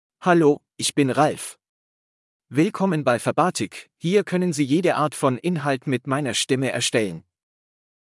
RalfMale German AI voice
Ralf is a male AI voice for German (Germany).
Voice sample
Male
Ralf delivers clear pronunciation with authentic Germany German intonation, making your content sound professionally produced.